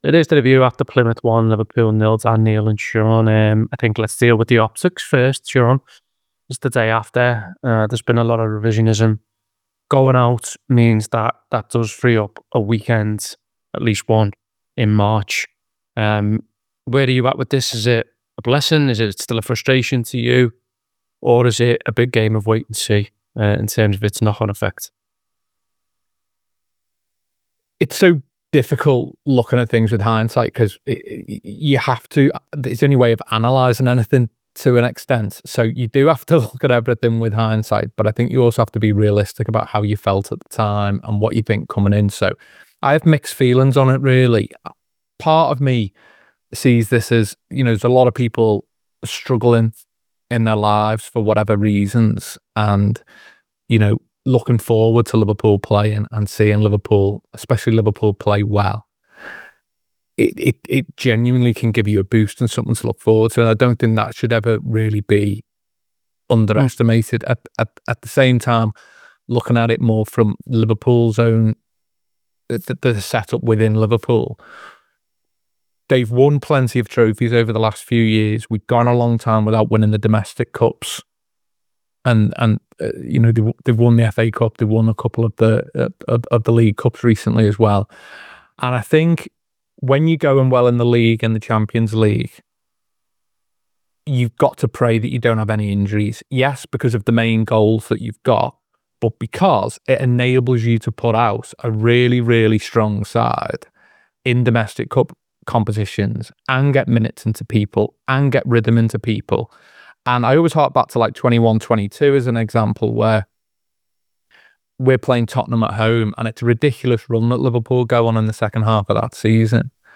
Below is a clip from the show – subscribe to The Anfield Wrap for more review chat around Plymouth Argyle 1 Liverpool 0…